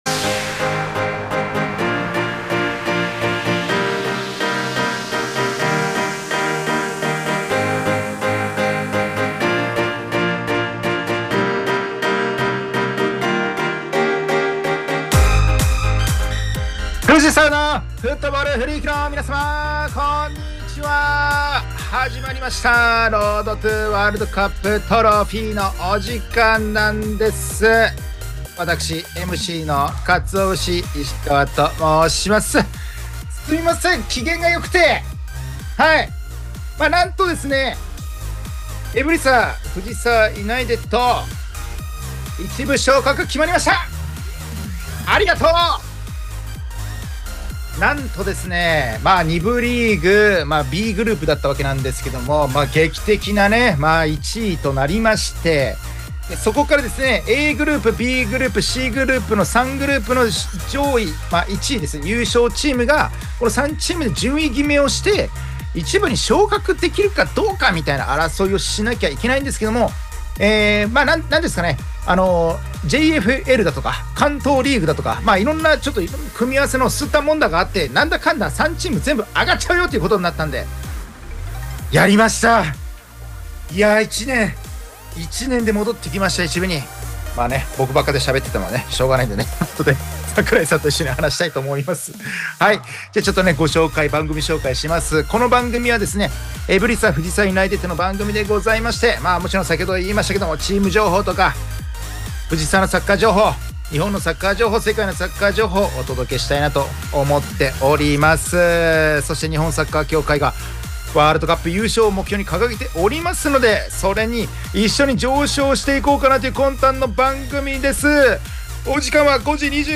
エブリサ藤沢ユナイテッドが提供する藤沢サッカー専門ラジオ番組『Road to WC Trophy』の第2期の第88回放送が12月5日(金)17時に行われました☆